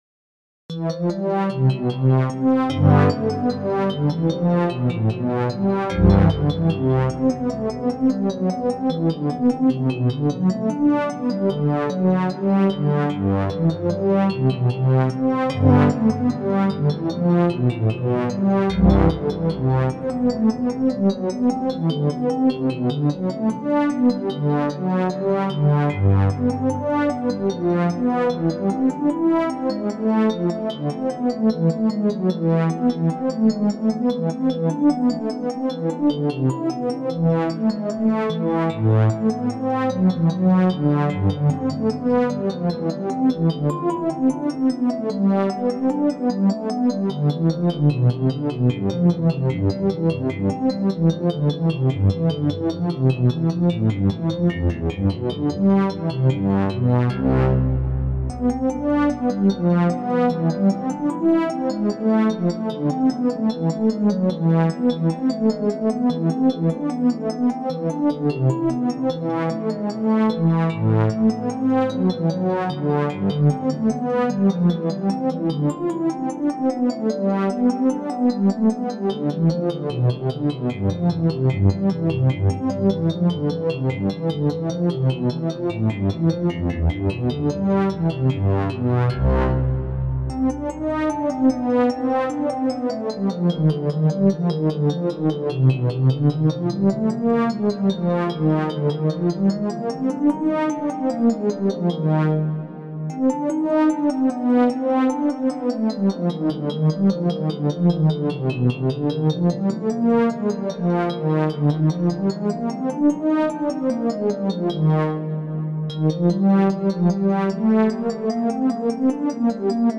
Bach Synthesizer